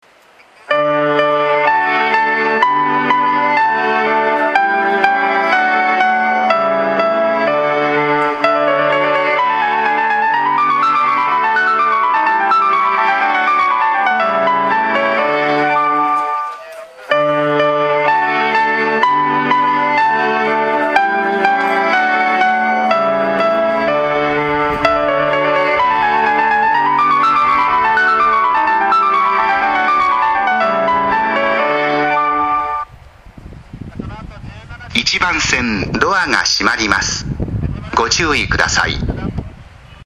１番線常磐線
発車メロディー２コーラスです。